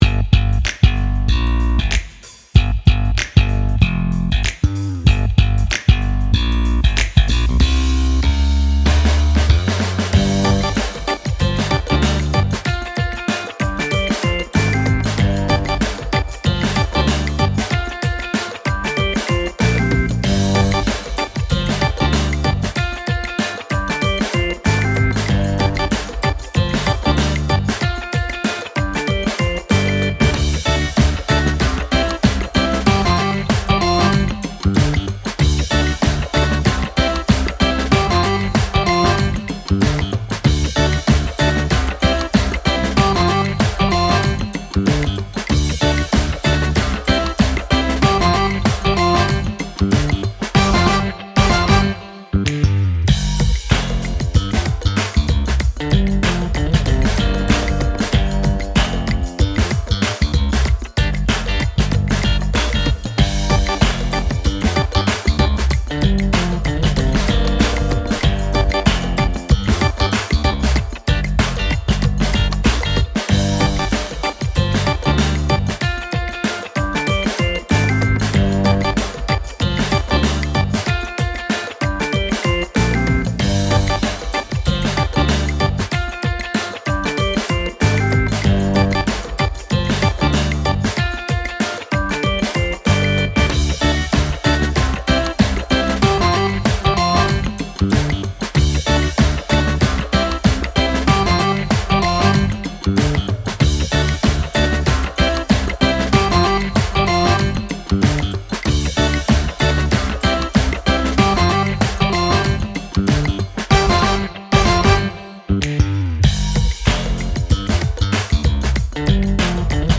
ファンク系フリーBGM